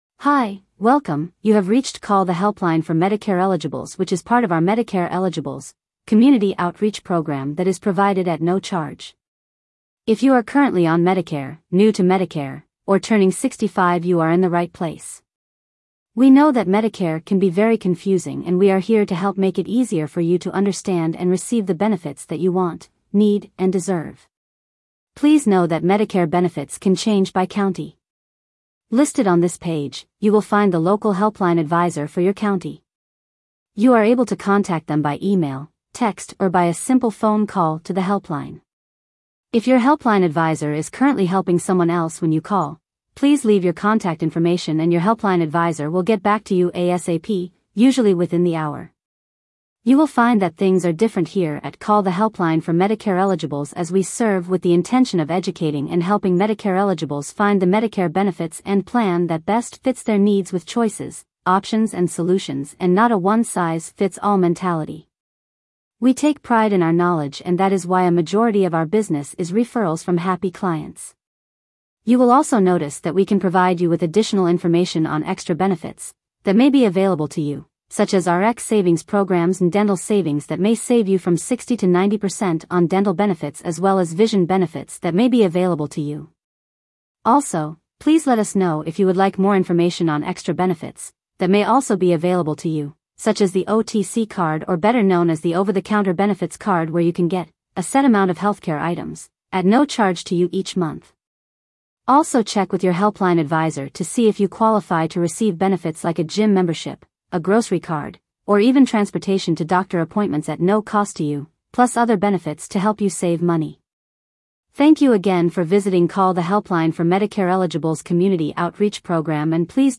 HelplineAdvisor-Female.mp3